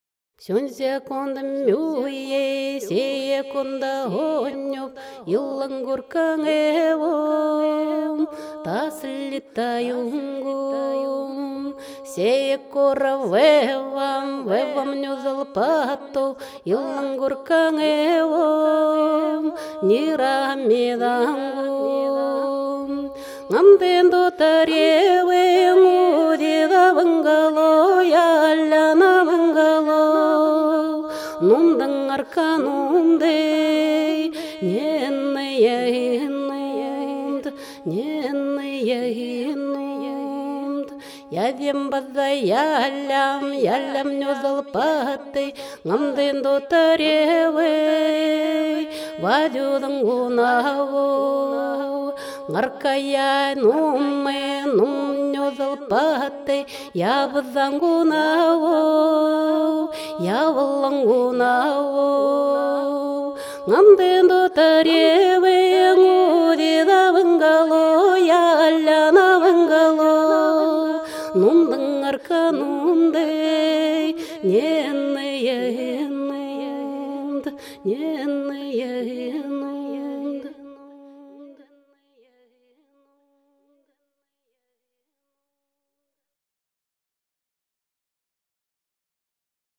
Пожалуйста, чёрный фон и музыка (ненецкая народная песня):